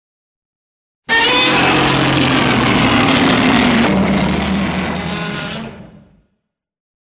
heisei Godzilla roar 1.mp3